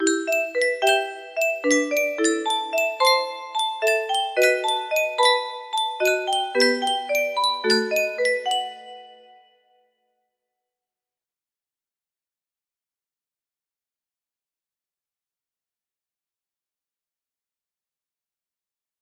Clone of looks good music box melody